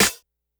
Snares
snr_01.wav